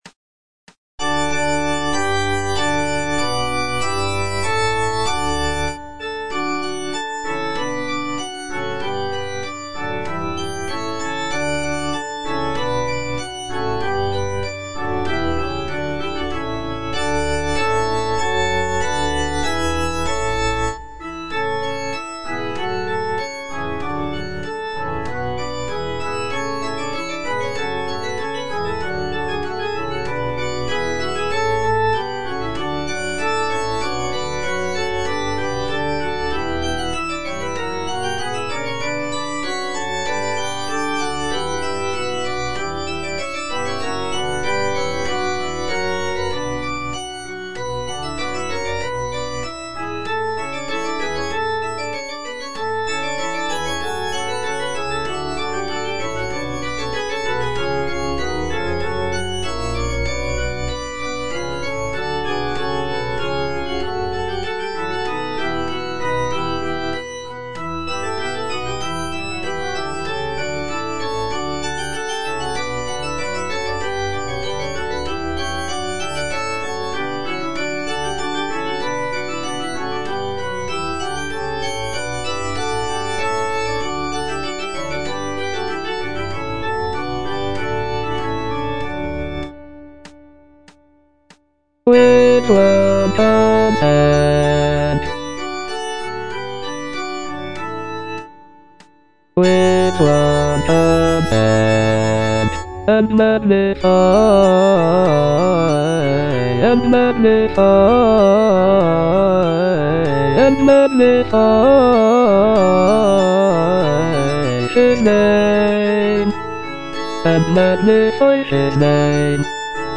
Choralplayer playing O praise the Lord with one consent - Chandos anthem no. 9 HWV254 (A = 415 Hz) by G.F. Händel based on the edition CPDL #08760
G.F. HÄNDEL - O PRAISE THE LORD WITH ONE CONSENT - CHANDOS ANTHEM NO.9 HWV254 (A = 415 Hz) O praise the Lord - Bass (Voice with metronome) Ads stop: auto-stop
The work is typically performed with historical performance practices in mind, including the use of a lower tuning of A=415 Hz to replicate the sound of Handel's time.